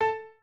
piano2_10.ogg